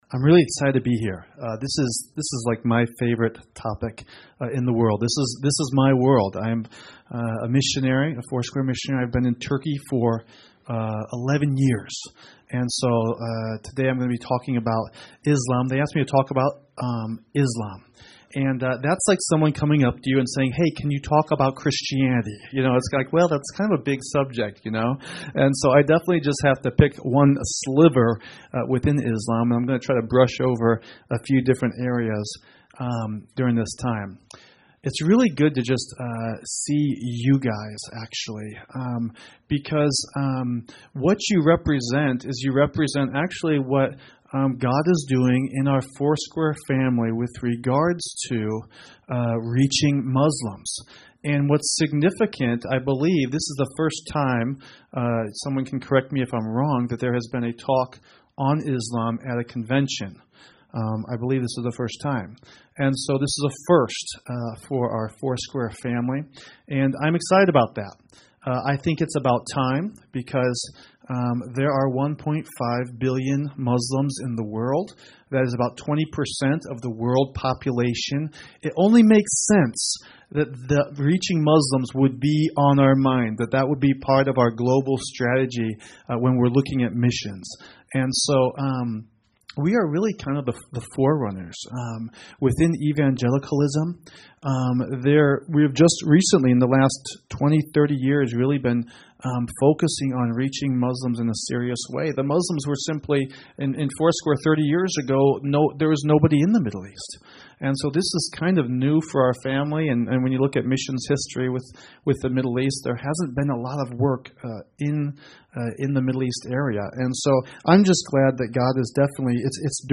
Capture five crucial insights about Islam to help you love and reach Muslims, whether in your neighborhood or across the world, in this live recording from Foursquare Connection 2018.
Acquire an understanding of how to engage Muslims in the U.S. and abroad, in this workshop recorded live at Foursquare Connection 2018.